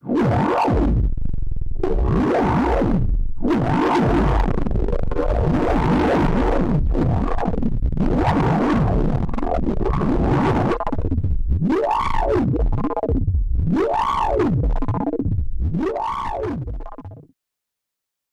The sounds are mostly space-age, weird naughty noises, and buzzy things -- cutting edge for 1976.